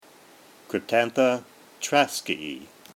Pronunciation/Pronunciación:
Cryp-tán-tha  trás-ki-ae